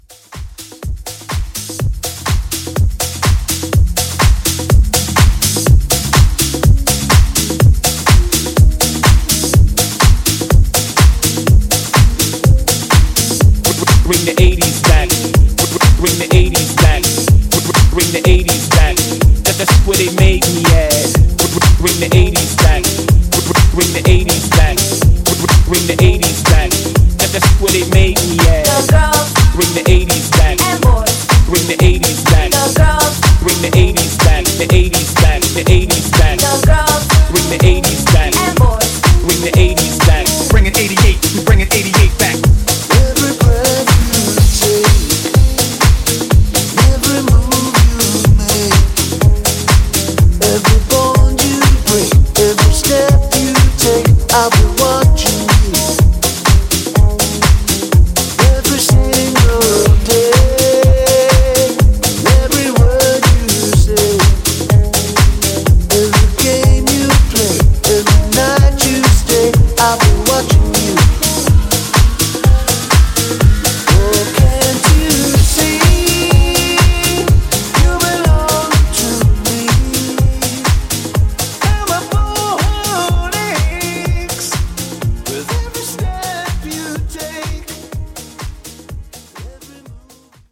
Genres: HIPHOP , RE-DRUM
Clean BPM: 79 Time